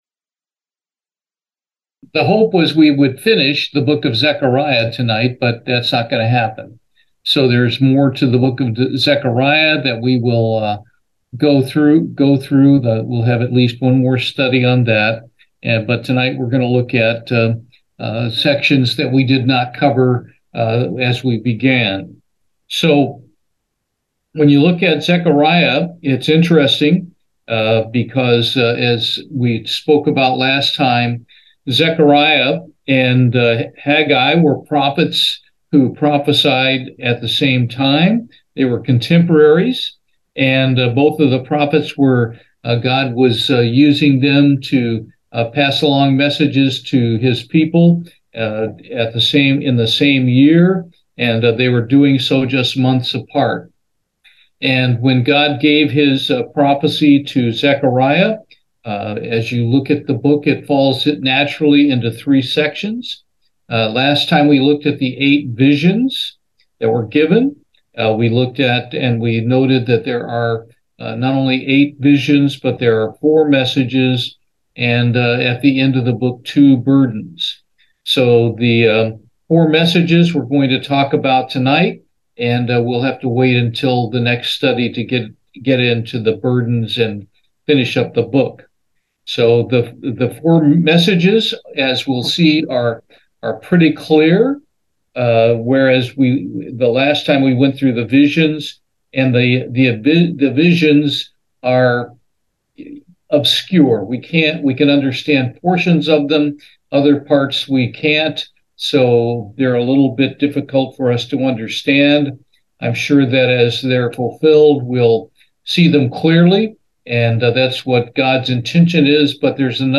Bible Study, Zechariah, Part 2